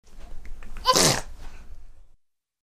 Звуки чихания
Звук детского чиха